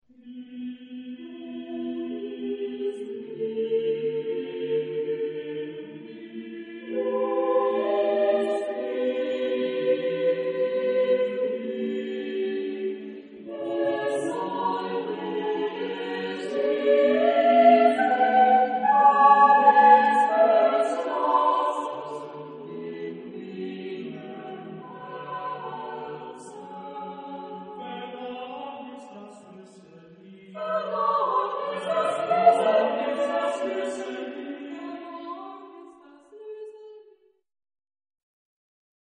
Genre-Style-Form: Cycle ; Choral song ; Secular
Type of Choir: SATB  (4 mixed voices )
Tonality: free tonality